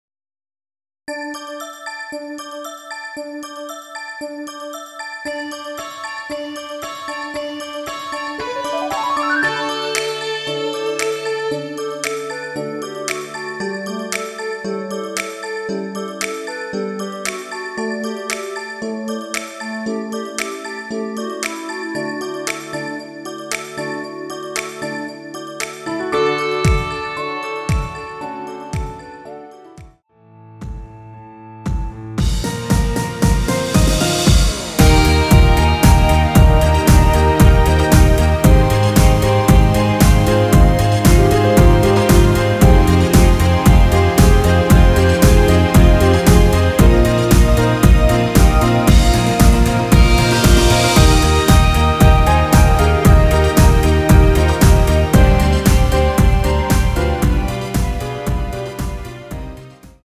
엔딩이 페이드 아웃이라 노래 부르기 좋게 엔딩 만들었습니다.
원키에서(-1)내린 멜로디 포함된 MR입니다.
앞부분30초, 뒷부분30초씩 편집해서 올려 드리고 있습니다.
중간에 음이 끈어지고 다시 나오는 이유는